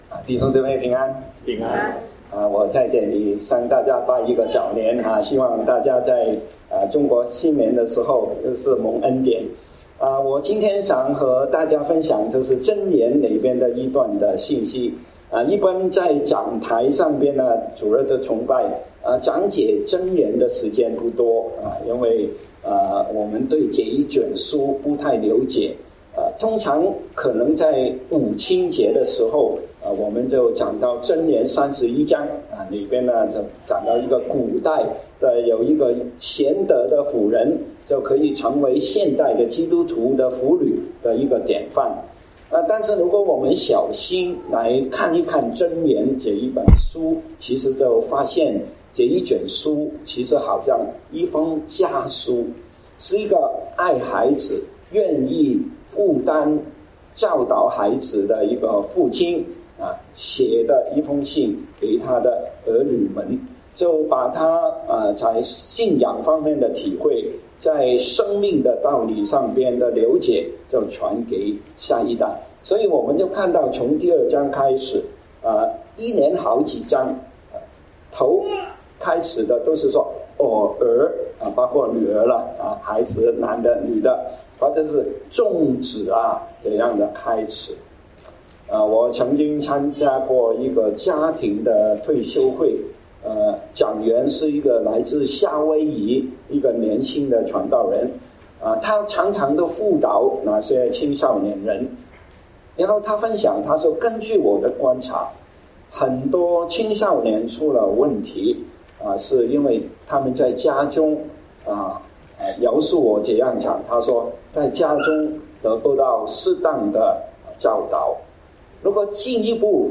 華埠粵語三堂